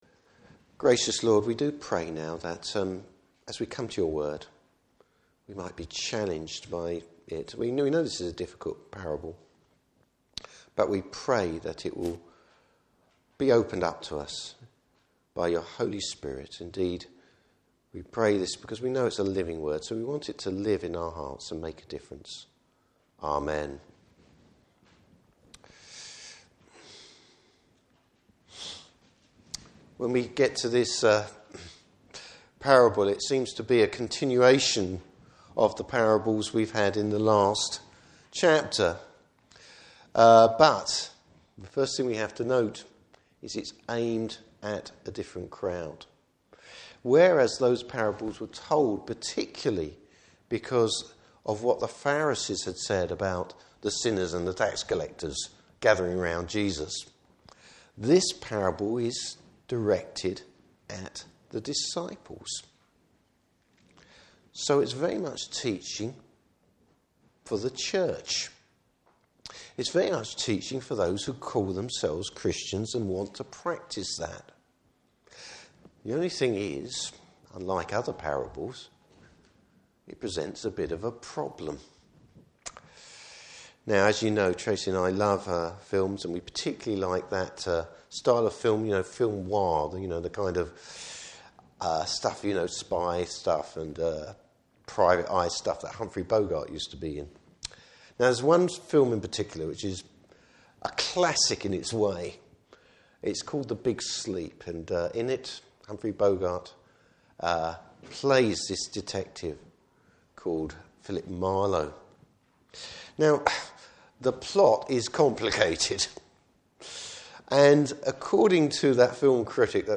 Service Type: Morning Service Bible Text: Luke 16:1-15.